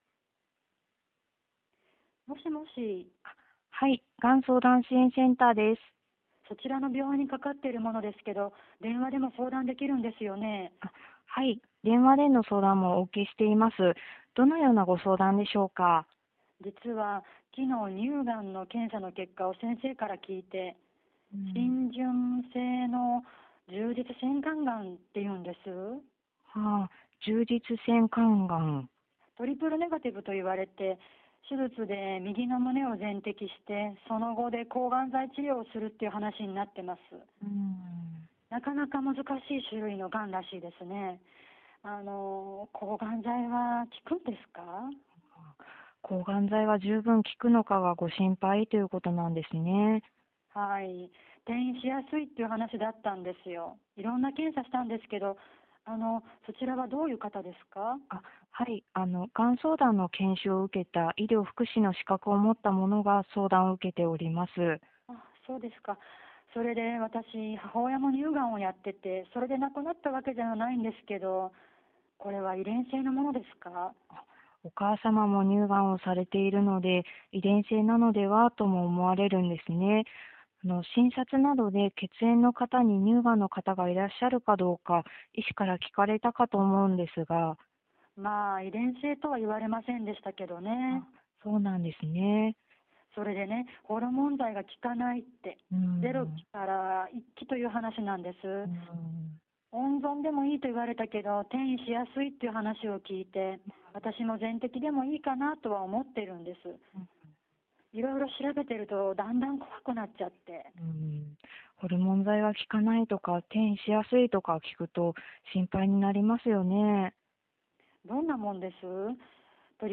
関連情報 事前課題を実施する際の留意点 電話相談事例（事例3）（11分07秒） 電話相談事例逐語録（事例3） がん相談対応評価表 問い合わせ先 本事例を使用して研修を開催される主催者へお問い合わせください。 電話相談事例の取り扱い 事前課題の事例は模擬事例ですが、保存等されたデータは研修会終了後に各自、破棄していただきますようお願い申し上げます。